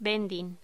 Locución: Vending